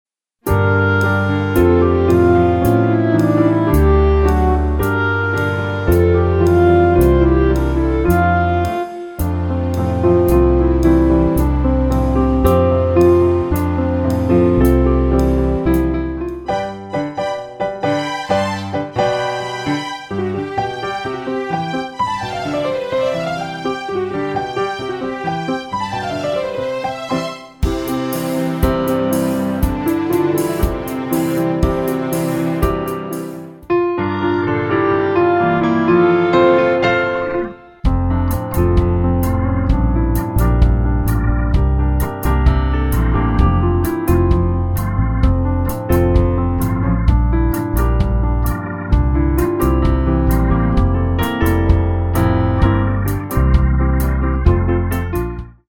Аудио монтаж Категория: Аудио/видео монтаж
Здесь выложу пример фонограммы для школьного учителя музыки (сделал одним миксом с плавными переходами)